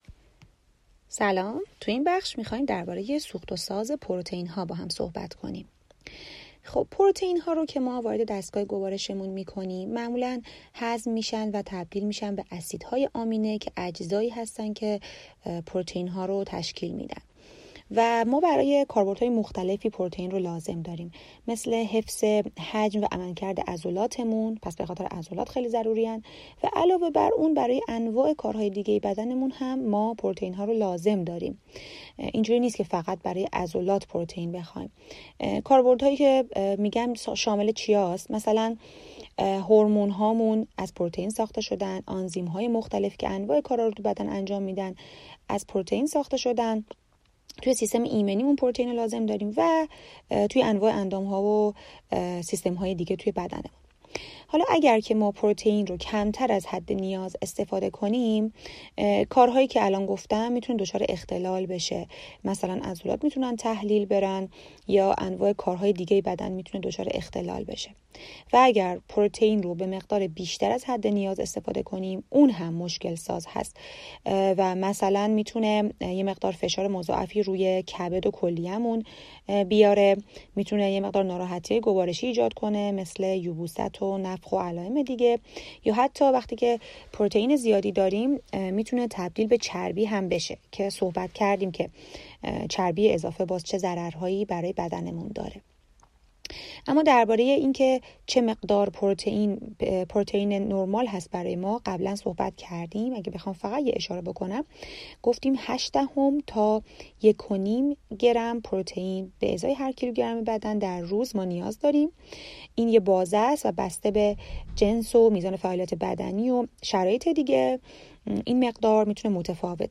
یک فایل برای آشنایی شما با کار و برآورد ادیت مورد نظر پیوست شده است.